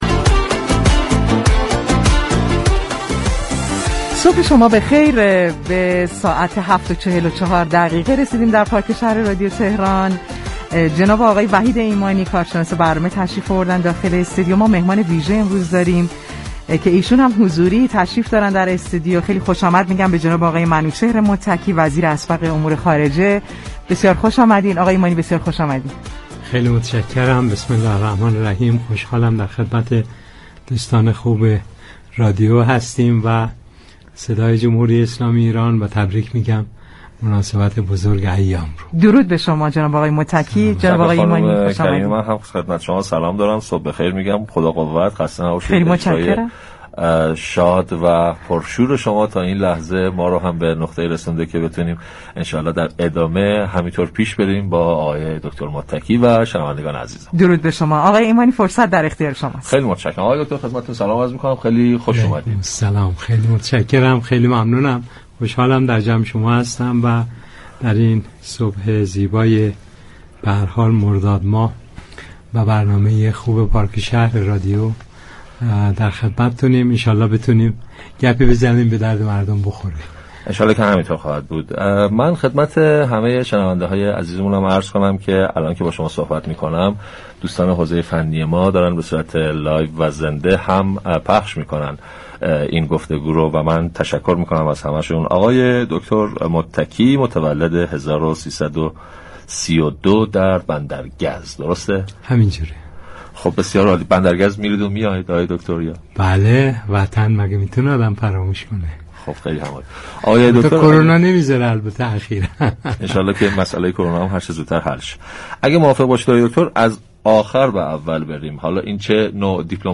منوچهر متكی وزیر پیشین امور خارجه با حضور در استودیو پخش زنده رادیو تهران در روز چهارشنبه 6 مرداد در گفتگو با برنامه پارك شهر رادیو تهران